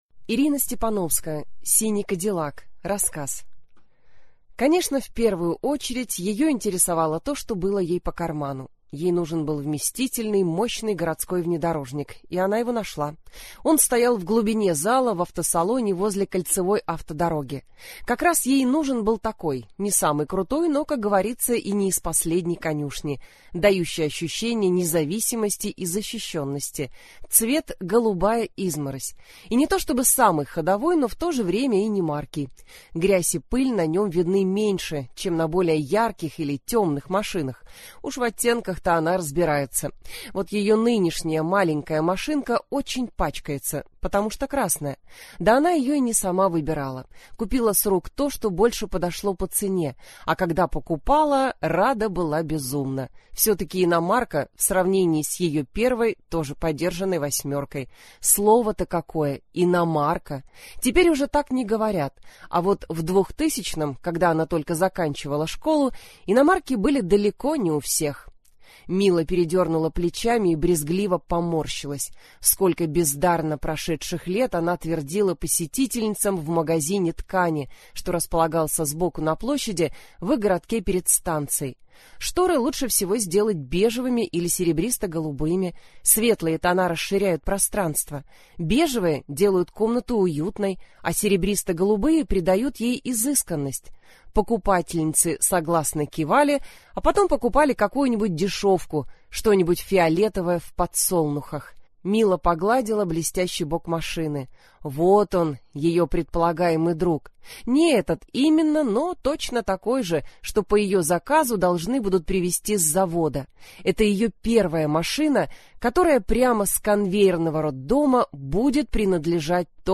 Аудиокнига Синий кадиллак | Библиотека аудиокниг